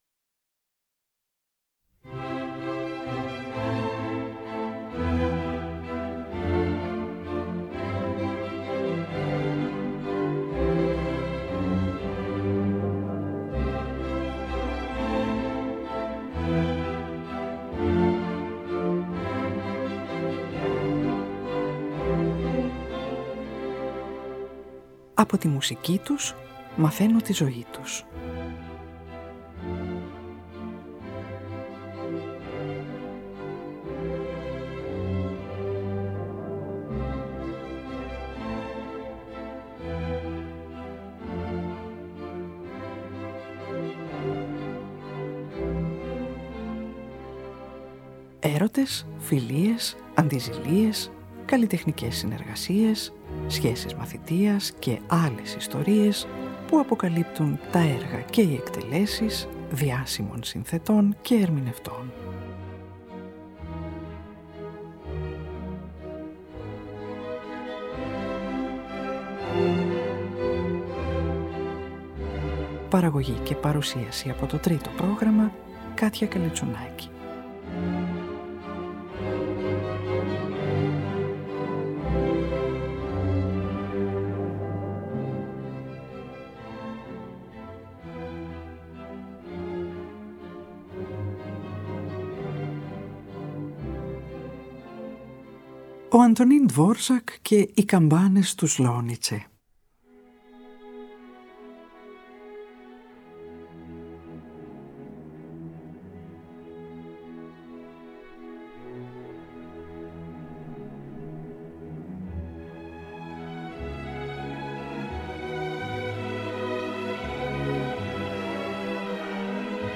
Την 1η Συμφωνία με το μοτίβο των καμπανών του Ευαγγελισμού που ο Dvořák θεωρούσε χαμένη μέχρι το τέλος της ζωής του, παίζει η Φιλαρμονική Ορχήστρα της Γερμανικής Ραδιοφωνίας υπο τον Karel Mark Chichon.